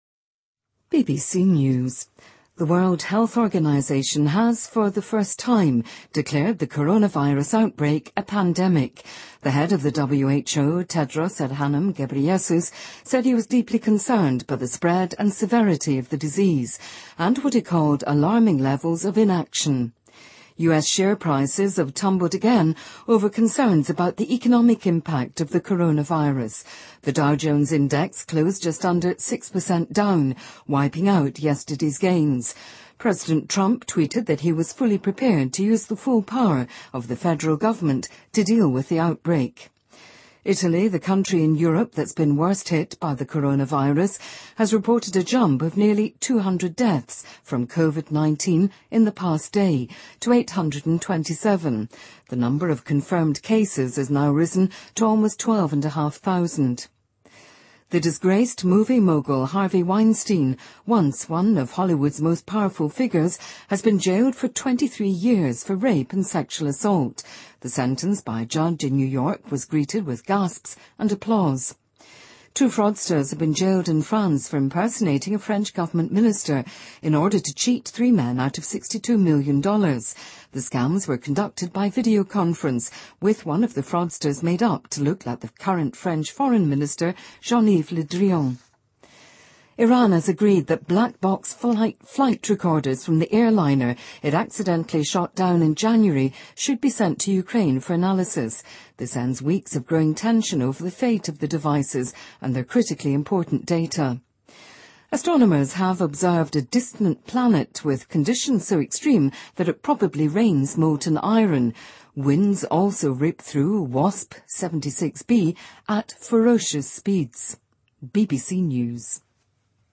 英音听力讲解:世卫组织宣布新冠肺炎为全球性流行病